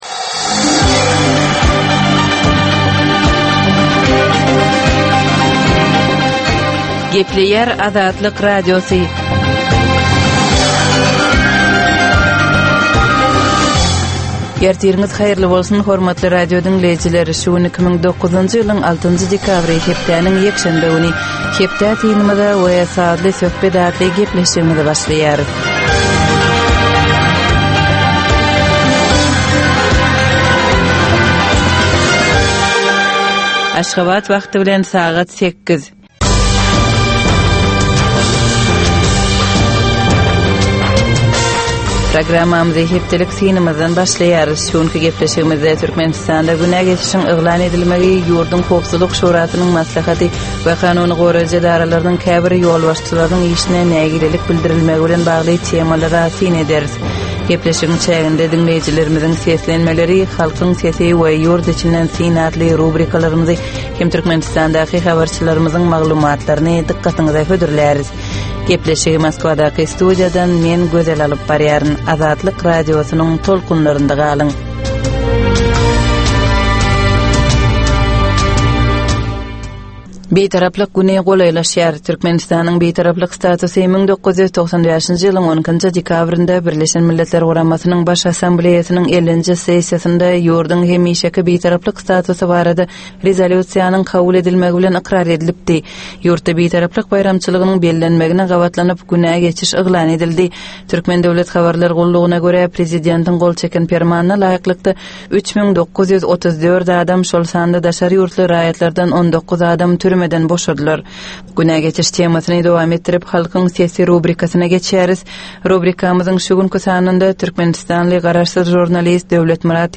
Tutuş geçen bir hepdäniň dowamynda Türkmenistanda we halkara arenasynda bolup geçen möhüm wakalara syn. 30 minutlyk bu ýörite programmanyň dowamynda hepdäniň möhüm wakalary barada gysga synlar, analizler, makalalar, reportažlar, söhbetdeşlikler we kommentariýalar berilýär.